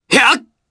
Fluss-Vox_Attack4_jp.wav